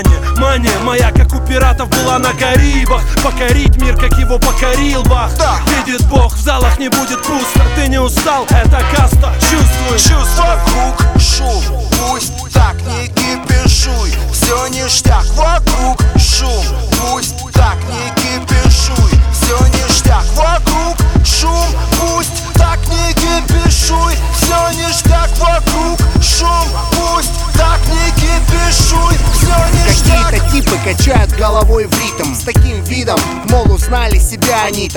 Жанр: Русский поп / Русский рэп / Русский шансон / Русские